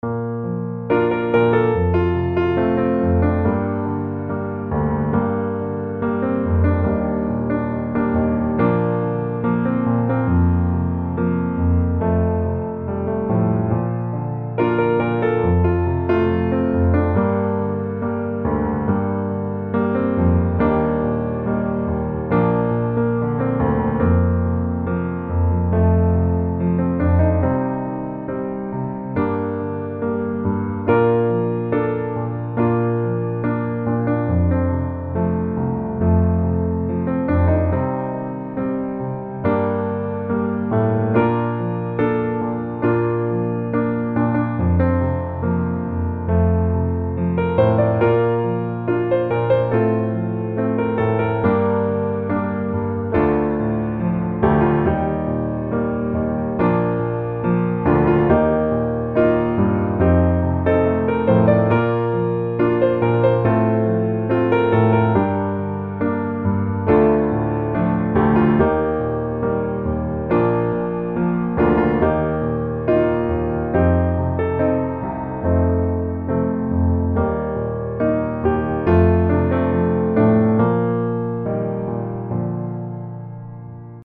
Bb Major